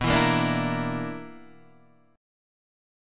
B Minor Major 7th Double Flat 5th